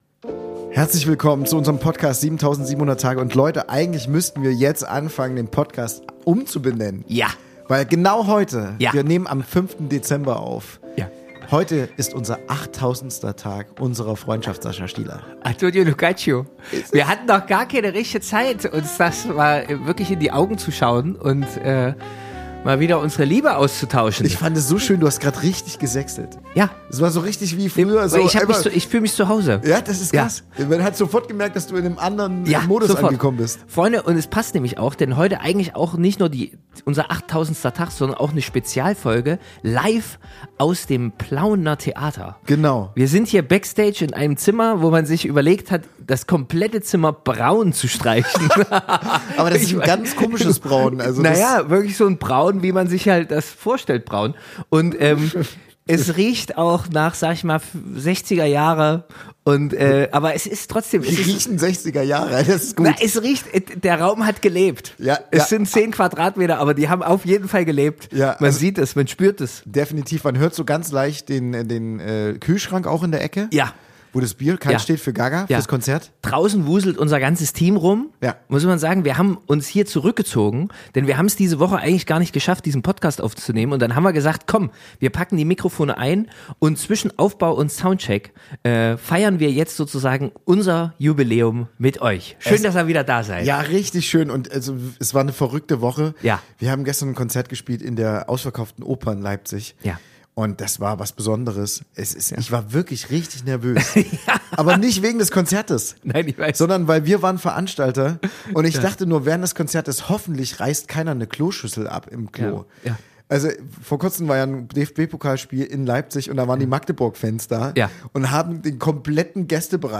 Der 8000. Tag unserer Freundschaft ist da – und natürlich feiern wir ihn stilecht: im Hinterzimmer des Plauener Theaters, umgeben von Aufbauchaos, Soundcheck-Echos und geschmierten Käsebrötchen.